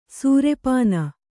♪ sūrepāna